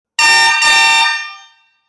Buzinas de Caminhão Triplo Som
• 03 cornetas;
• Som agudo e de alta frequência;
• Intensidade sonora 130db;
Som da Buzina